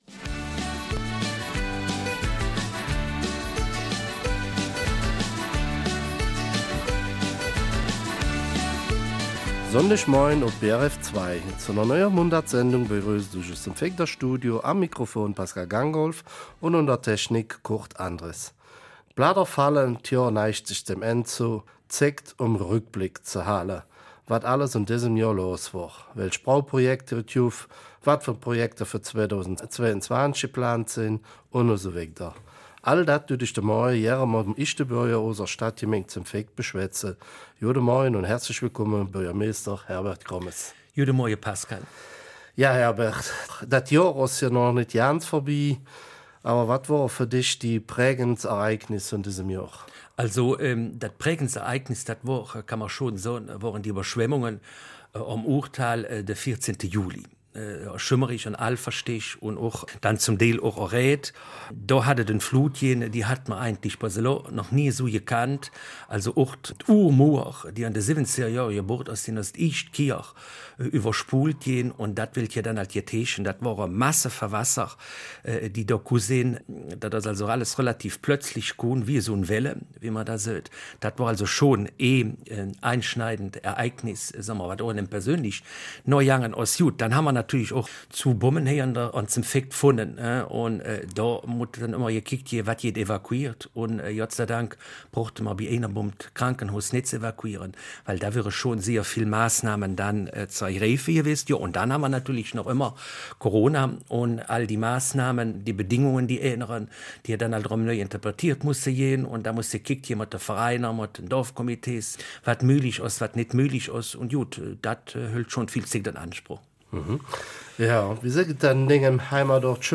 Er unterhält sich mit dem St. Vither Bürgermeister Herbert Grommes über die Flutkatastrophe, Bauprojekte und anstehende Projekte im nächsten Jahr in der Gemeinde.
Eifeler Mundart: Neues aus der Gemeinde St. Vith 23.